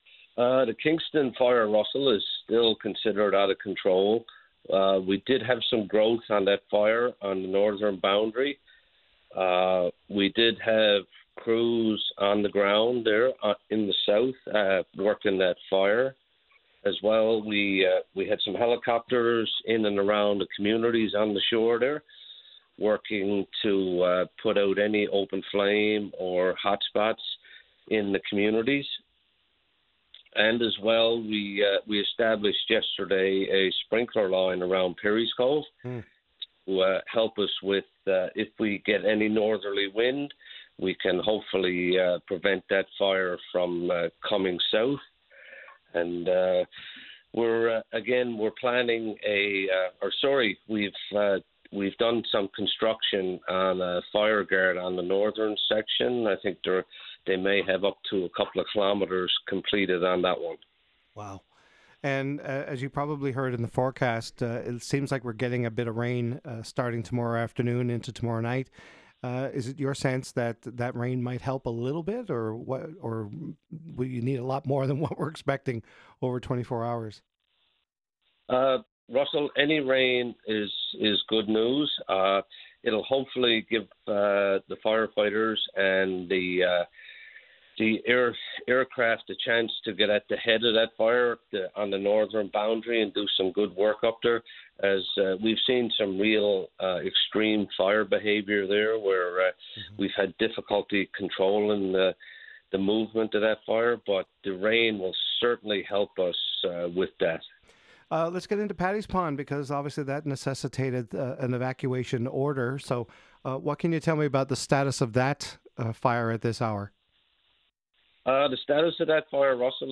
LISTEN: Fire Duty Officer Provides an Update on Your VOCM Mornings – Thursday, August 14, 2025